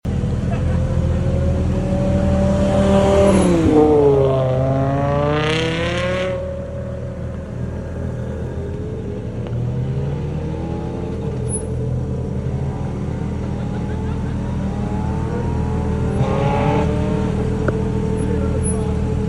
Ferrari 430 Scuderia Spider 16M sound effects free download